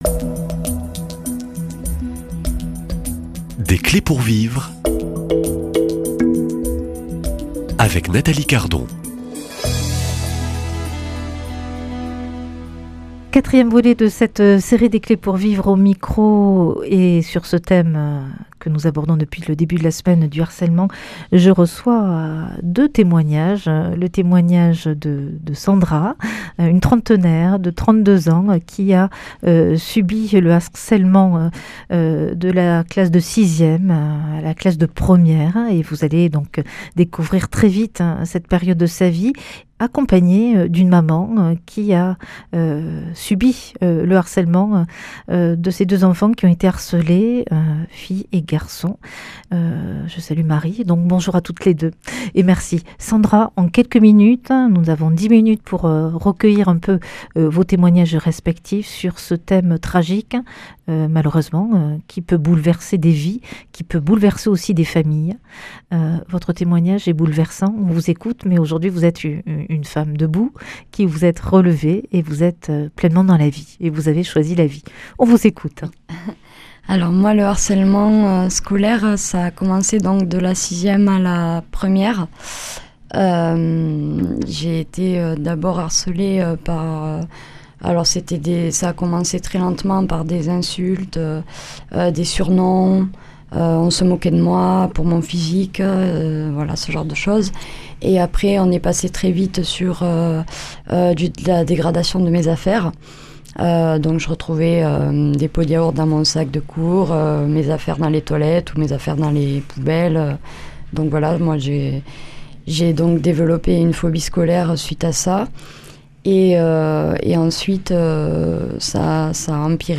Accueil \ Emissions \ Foi \ Témoignages \ Des clés pour vivre \ Re-choisir la vie après des années de harcèlement !